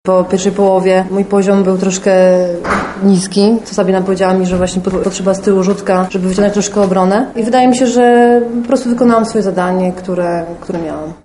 Oto pomeczowe wypowiedzi bohaterek spotkania: